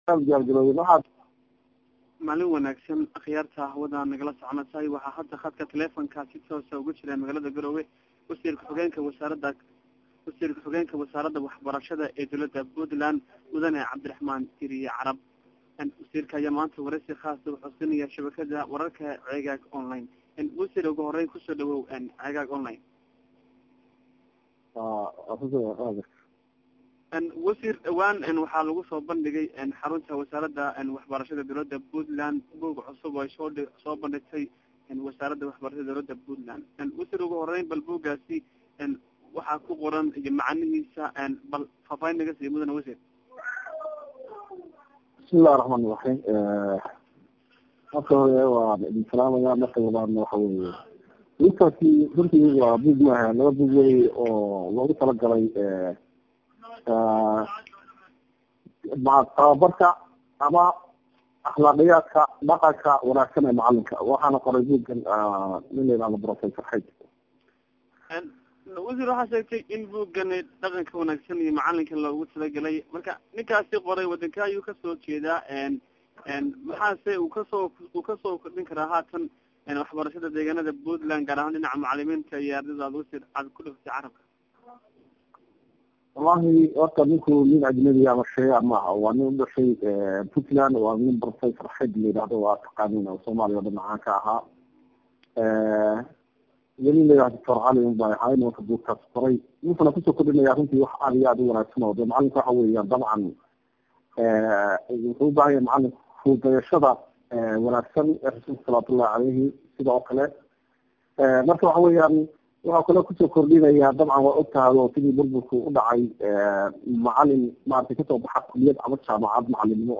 Wasiir kuxigeenka Waxbarashada Puntland oo wareysi khaas ah siisay Shabakada Ceegaag Online.